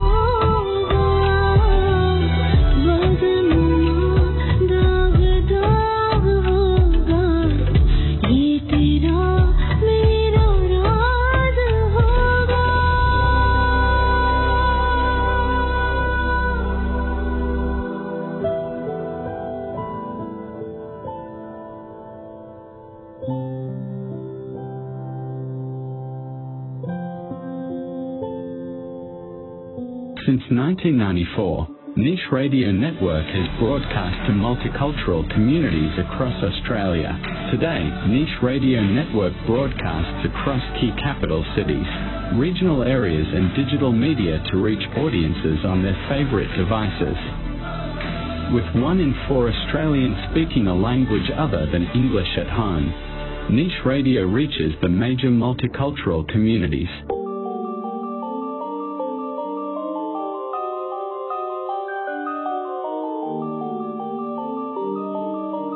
This is what 16kbs sounds like on a station here in Melbourne, in my opinion worse than a good sounding AM transmission.
Yeah geez… what is it with the letter “S” sounding so bad?